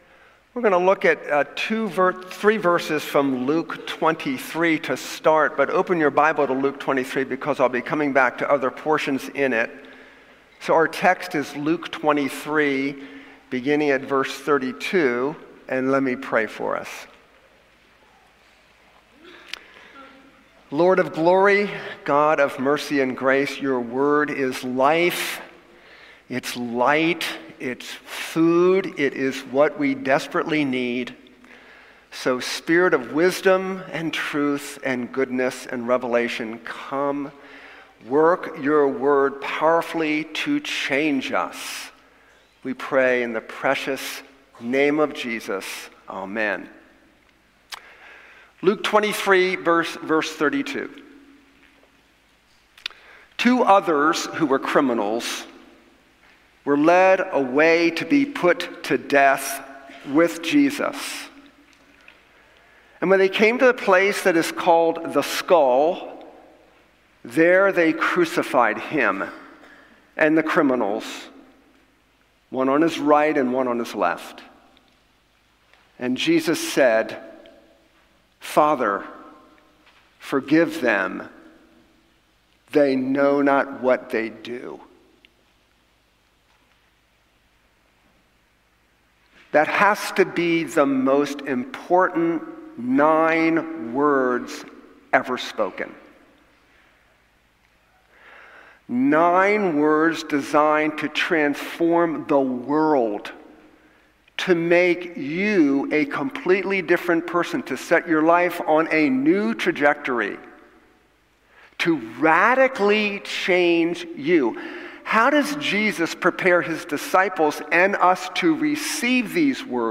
Passage: Luke 23:32-34 Service Type: Sunday Morning Download Files Bulletin « Resting in the Lord